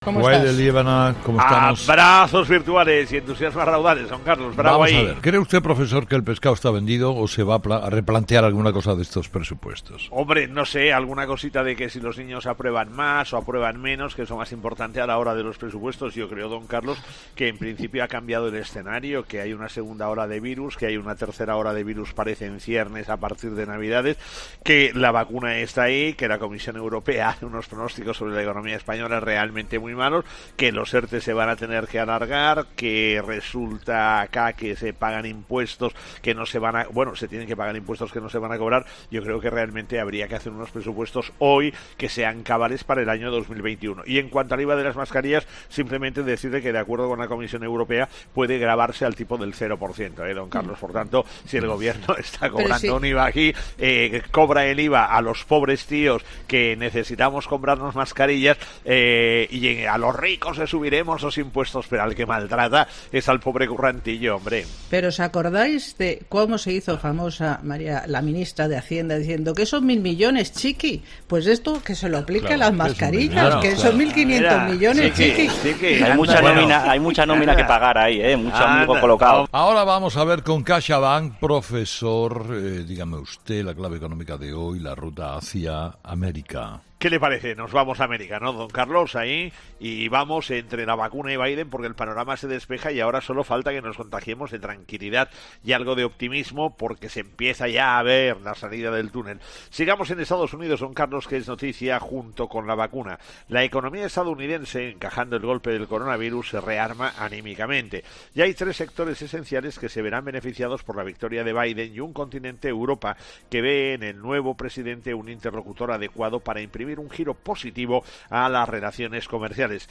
La actualidad económica en 'Herrera en COPE' con el profesor Gay de Liébana.